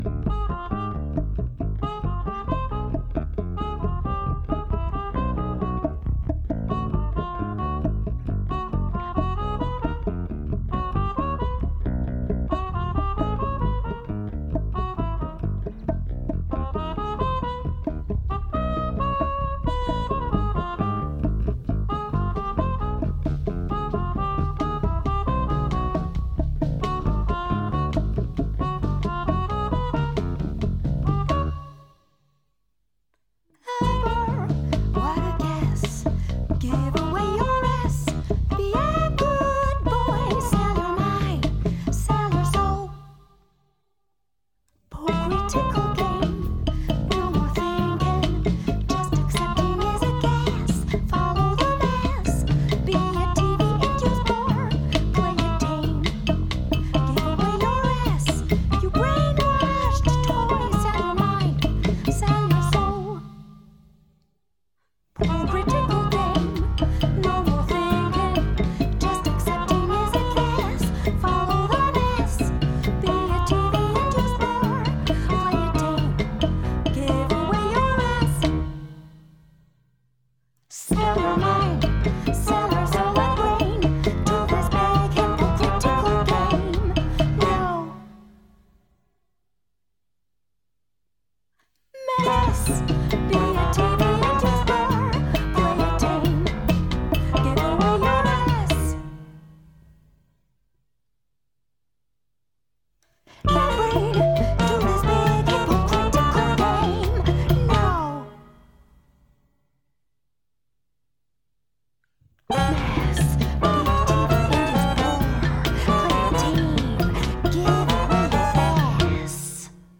accordeon / piano
trumpet
clarinets / soprano sax
cello
marimba
ebass
drums